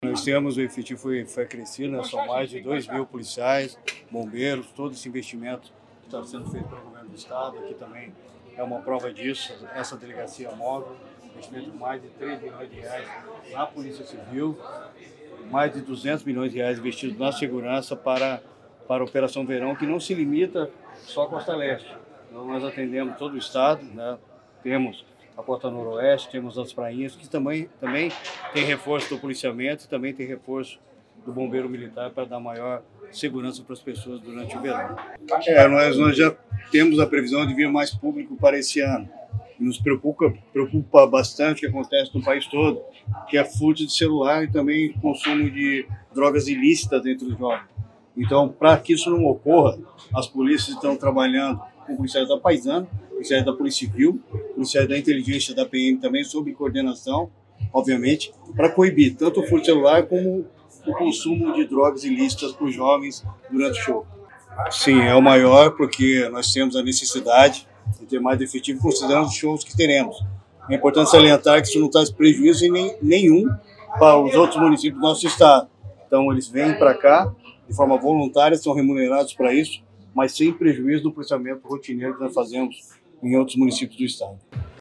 Sonora do secretário Estadual da Segurança Pública, Hudson Teixeira, sobre o efeito recorde nesta edição do Verão Maior Paraná